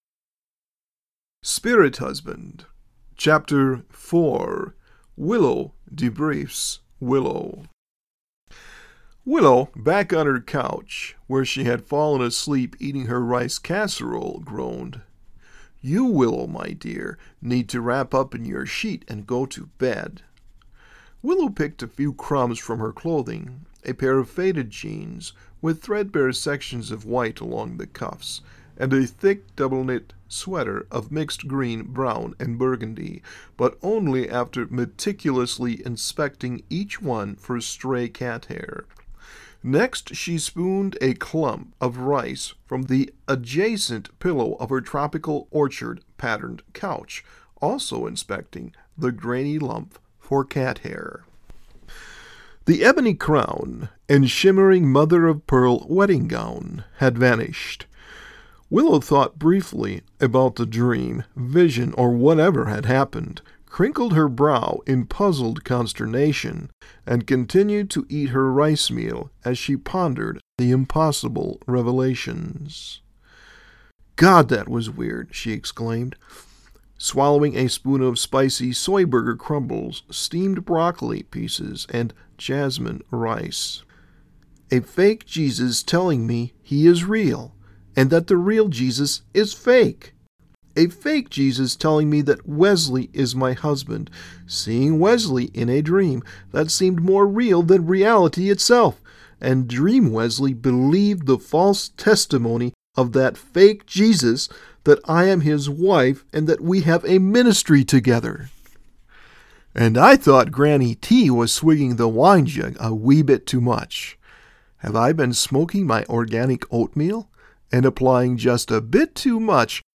Chapter 4 of  Spirit Husband Christian Fantasy Audio Book.